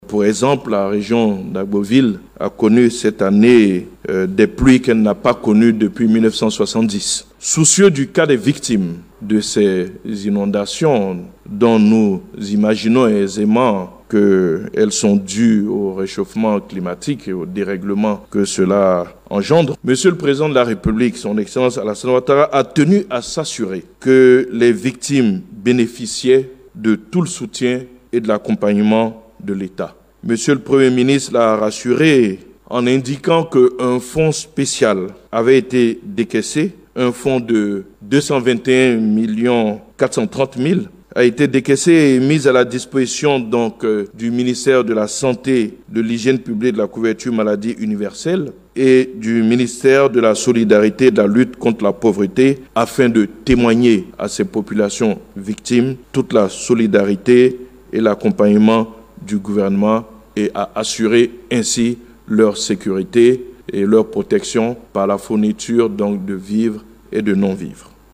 Le Porte-parole du Gouvernement a précisé qu’un fonds spécial à destination de 02 ministères, a été décaissé pour assister les victimes.